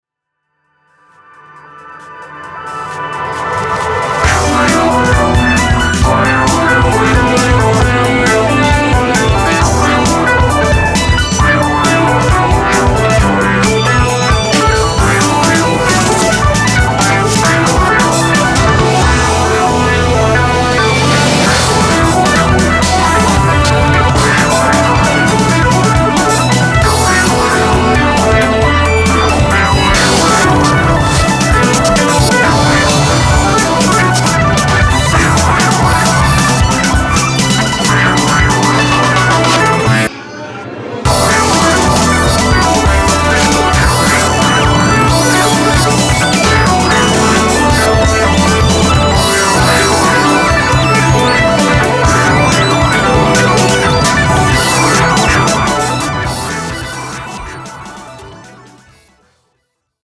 東京を中心に活動する二人組みユニット